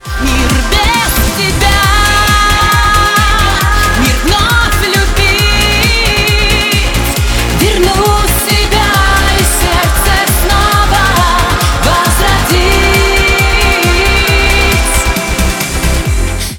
• Качество: 128, Stereo
громкие
попса